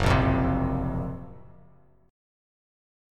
F#M7b5 chord